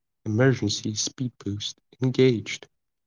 emergency-speedbost-engaged.wav